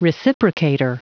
Prononciation du mot reciprocator en anglais (fichier audio)
Prononciation du mot : reciprocator